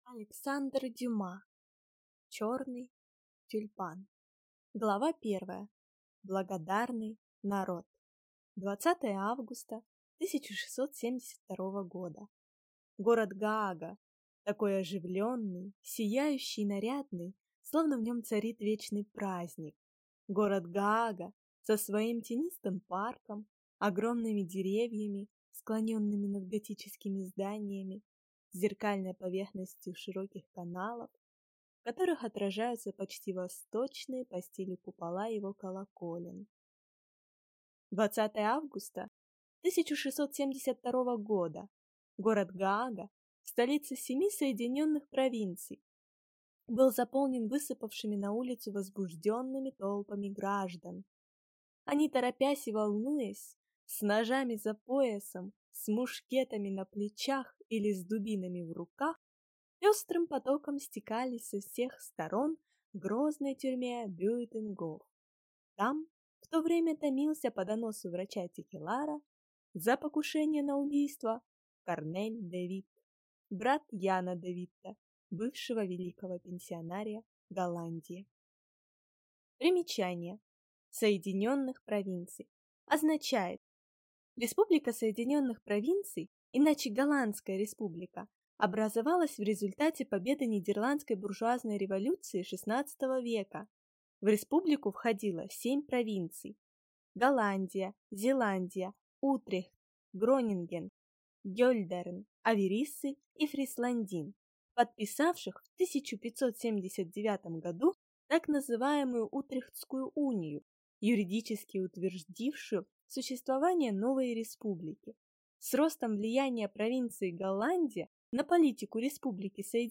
Аудиокнига Черный тюльпан | Библиотека аудиокниг